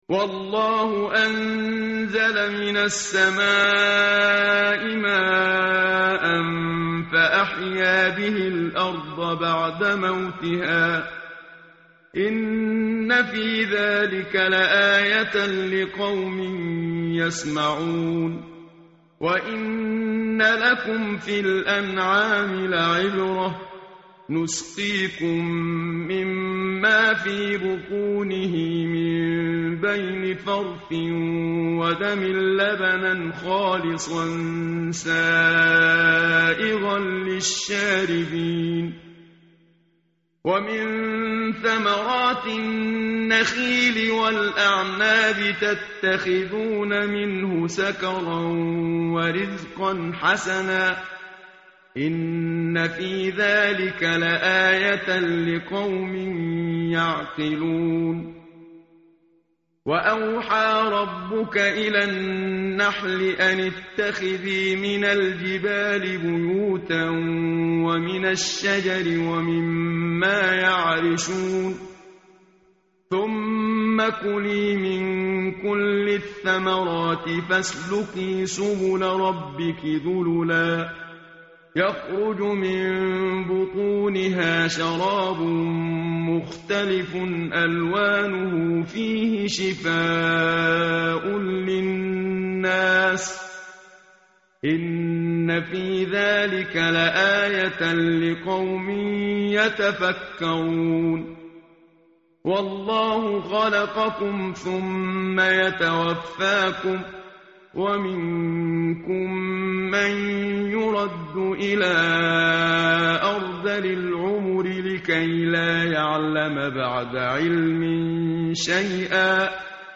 tartil_menshavi_page_274.mp3